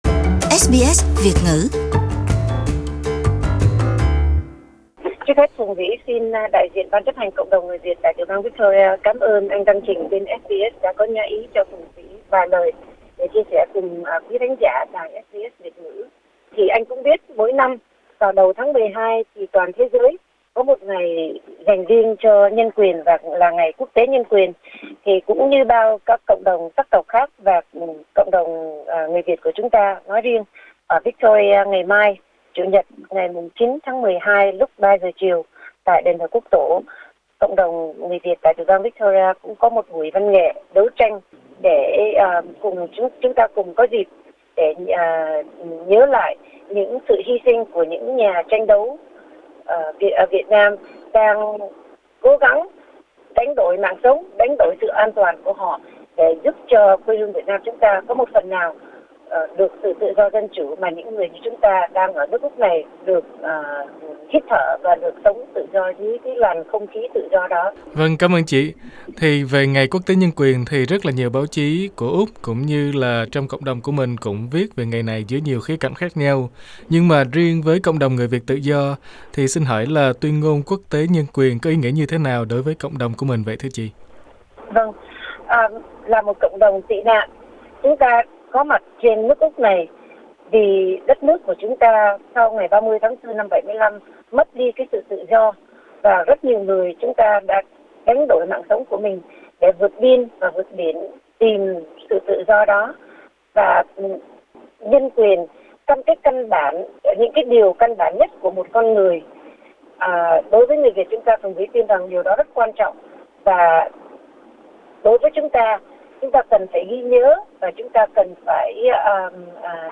trả lời phỏng vấn SBS Việt ngữ nhân Ngày Nhân quyền Quốc tế 2018.